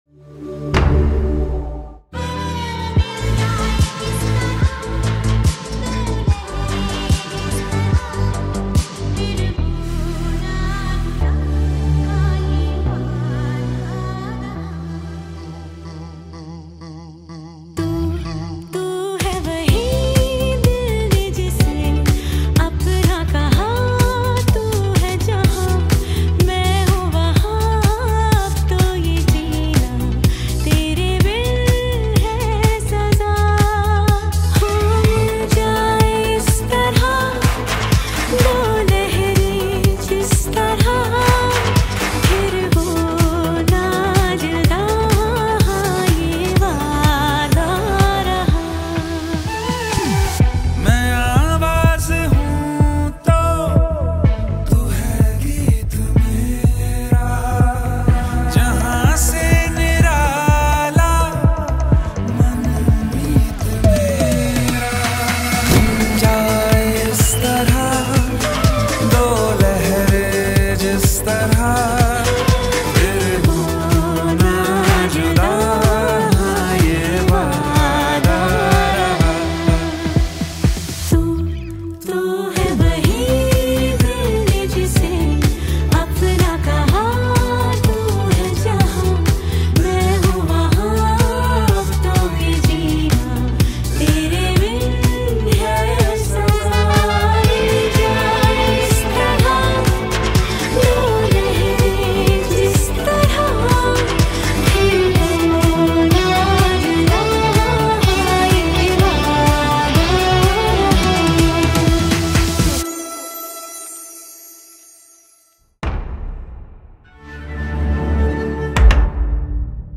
Releted Files Of Bollywood Mp3 Song